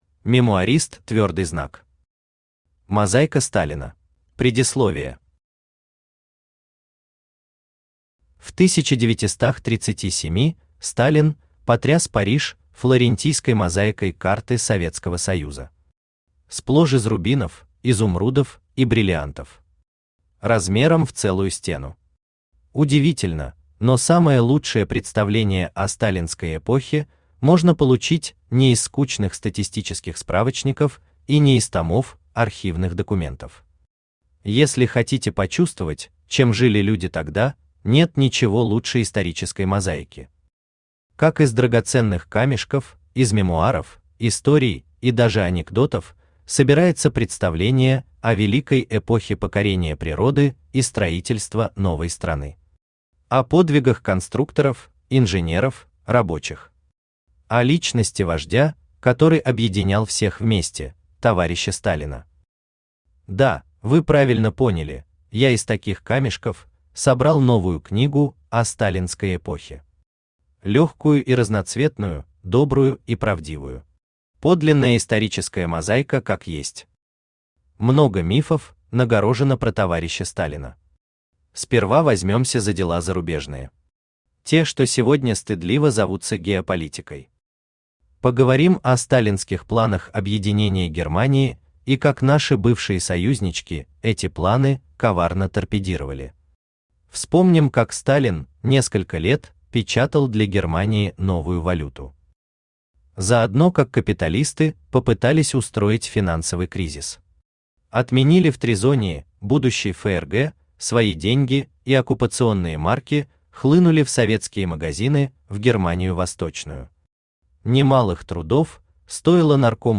Аудиокнига Мозаика Сталина | Библиотека аудиокниг
Aудиокнига Мозаика Сталина Автор МемуаристЪ Читает аудиокнигу Авточтец ЛитРес.